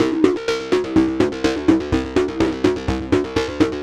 tx_synth_125_feedback_CD1.wav